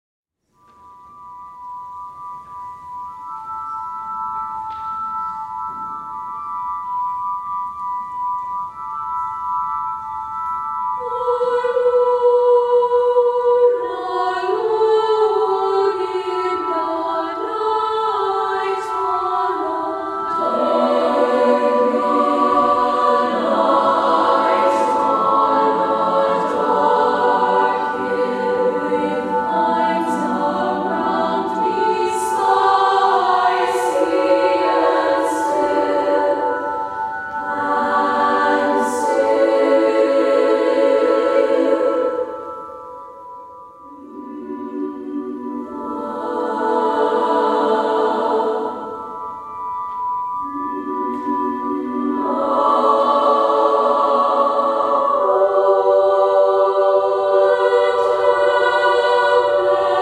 Piano.